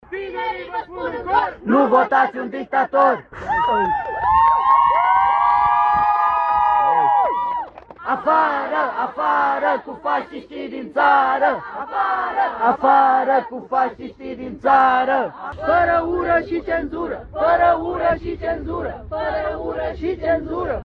Protestul împotriva mișcărilor extremiste a fost unul pașnic și la Iași.
Aproximativ 40 de persoane s-au strâns în Piața Unirii și au scandat mesaje împotriva ideologiei legionare.
Protest-Iasi.mp3